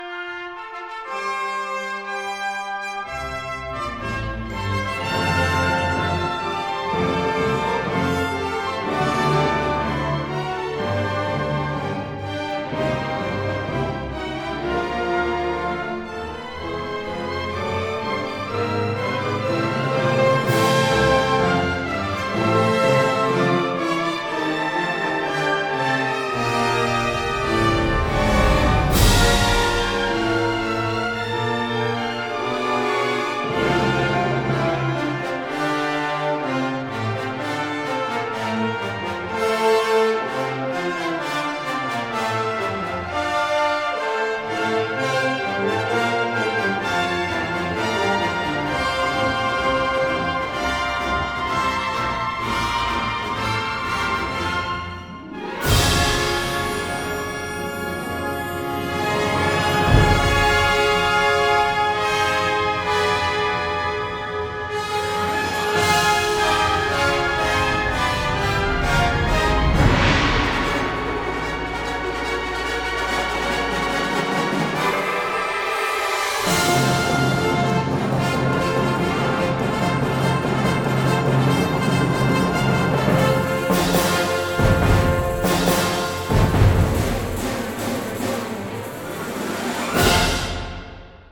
女低音